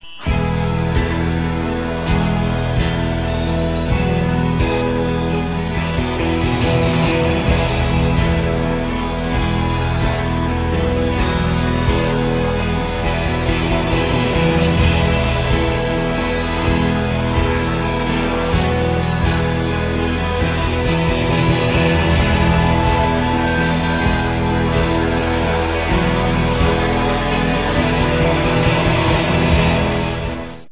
Woodwinds, tribal drums and an underlying ethereal sound
Loud, heartfelt and slightly wicked
tribal-ethereal-instrumental-rock-and-roll